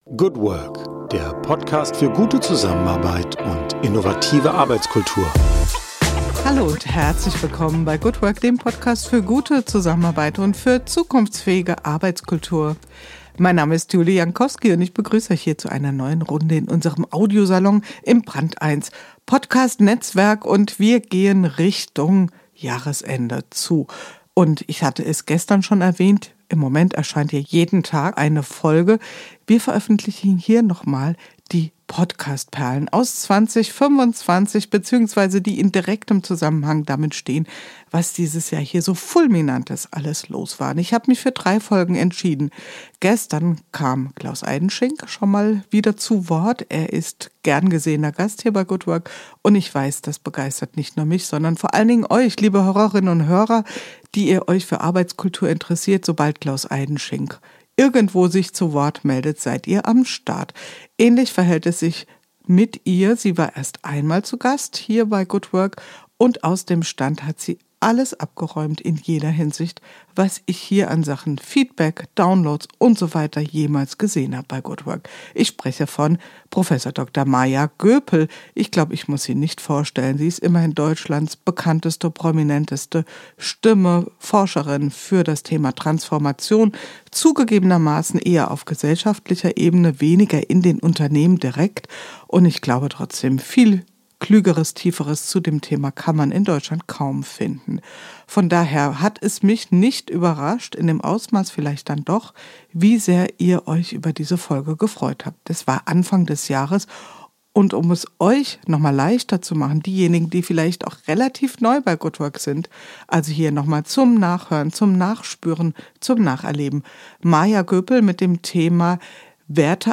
Die Transformationsforscherin Prof. Dr. Maja Göpel spricht über die Grundlagen echten Wandels.